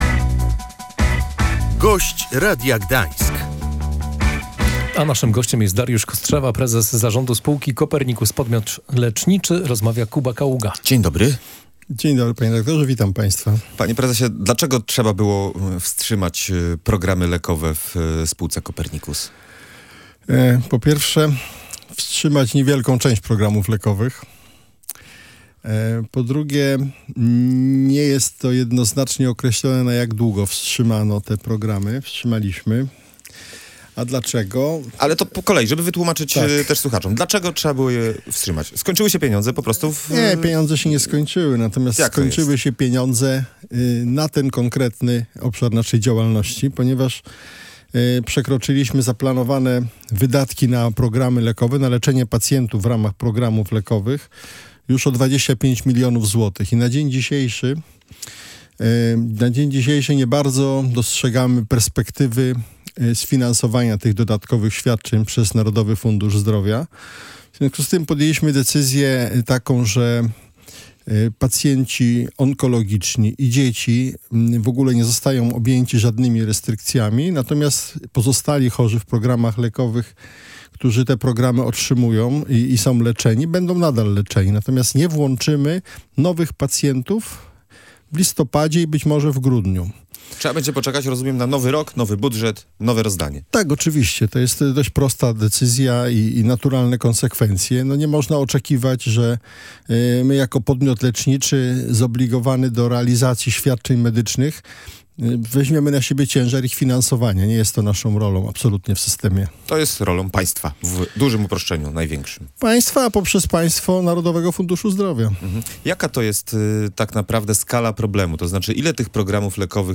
Jak podkreślał „Gość Radia Gdańsk”, chodzi o około 20 pacjentów.